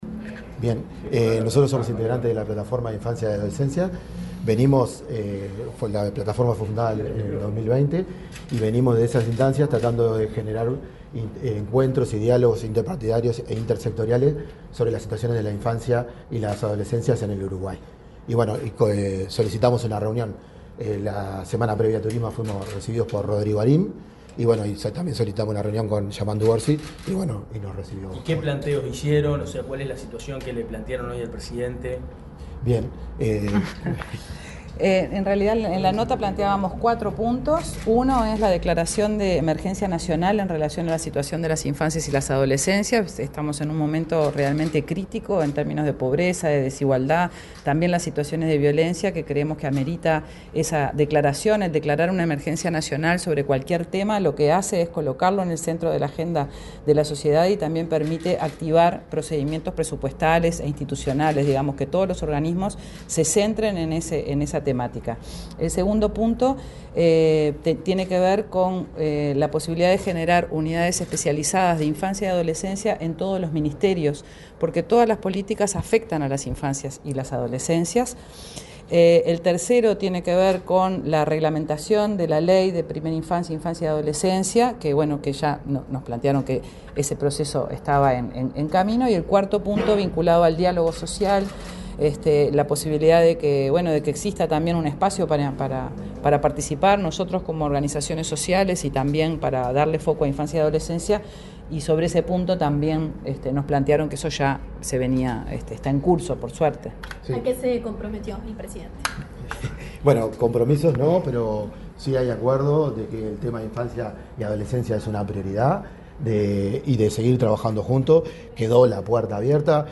dialogaron con la prensa en la Torre Ejecutiva